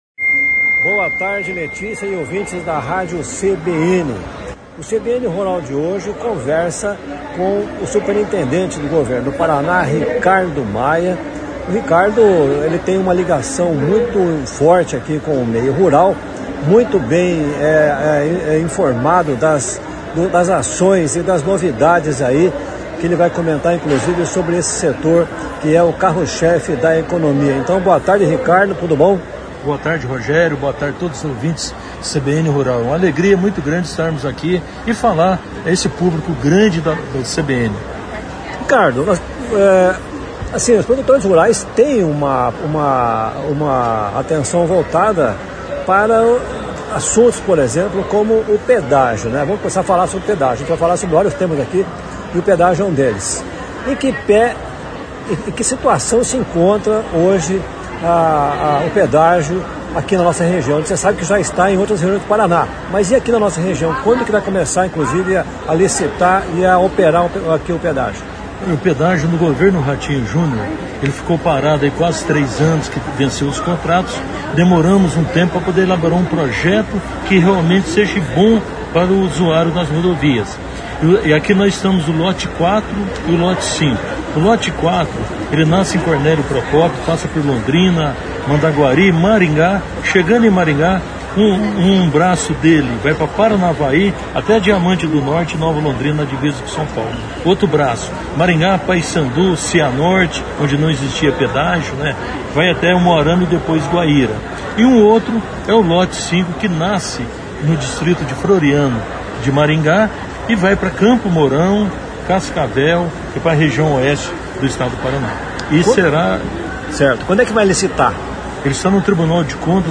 conversa com o superintendente do governo do Paraná, Ricardo Maia.